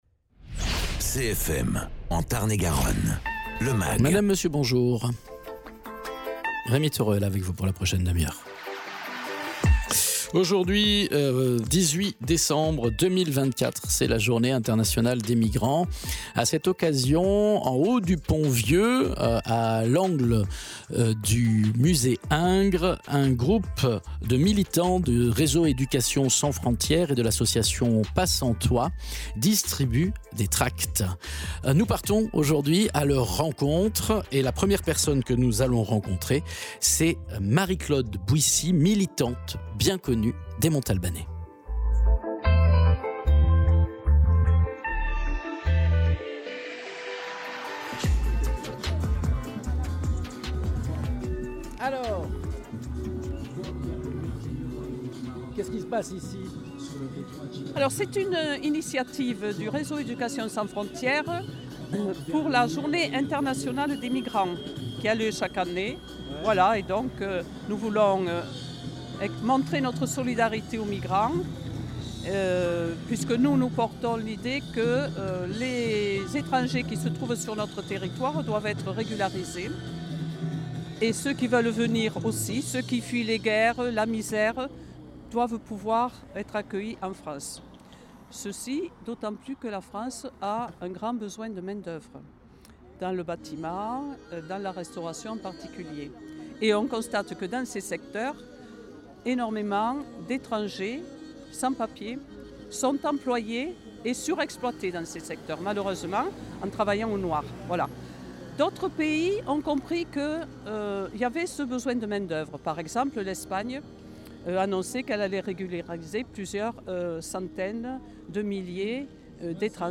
à l’occasion de la journée internationale des migrants, rencontre avec des militants du Réseau Education Sans Frontière, mènent une action de sensibilisation du grand public au carrefour du musée Ingres à Montauban...